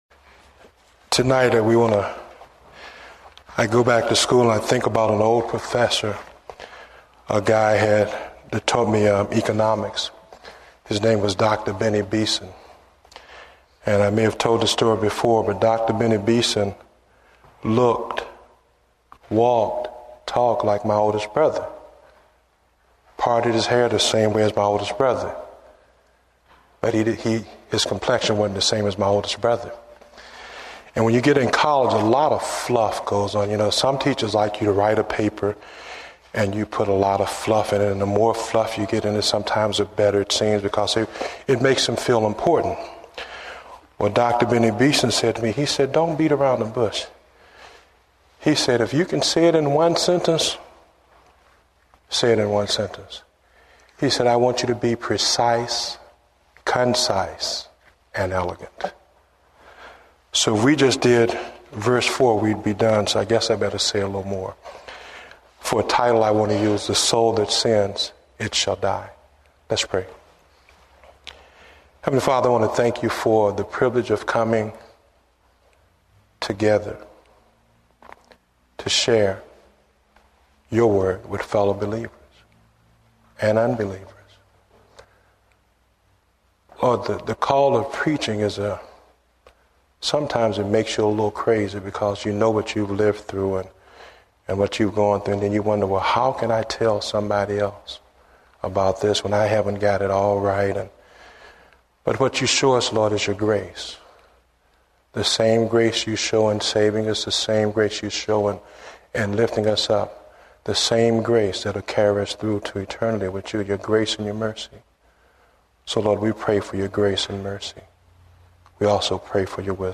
Date: November 23, 2008 (Evening Service)